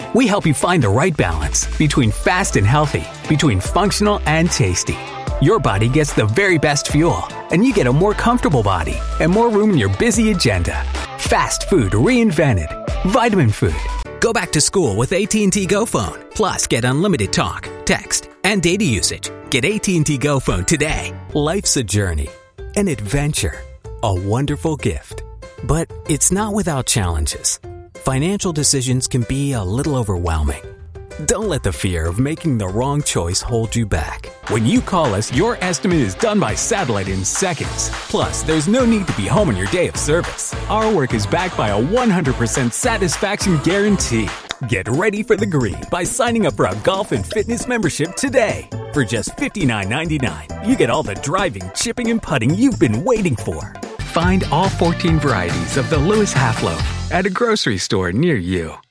Demo
Adult
standard us
commercial
friendly
reassuring